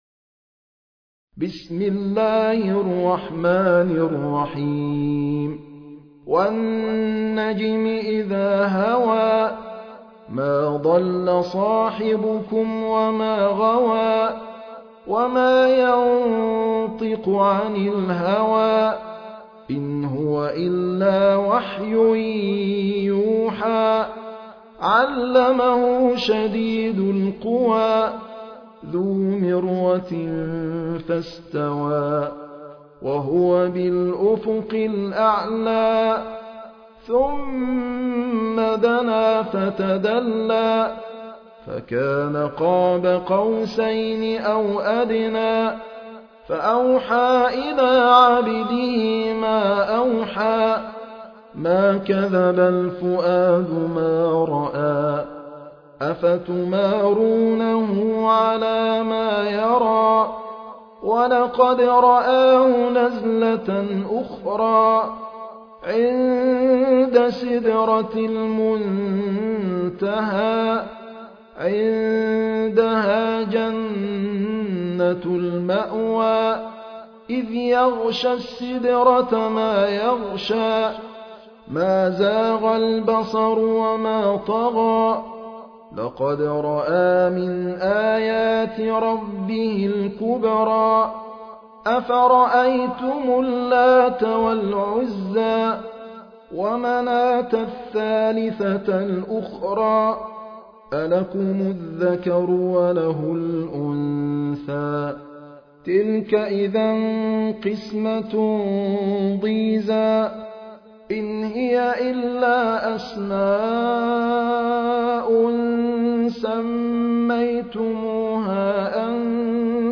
المصحف المرتل - حفص عن عاصم - An-Najm ( The Star )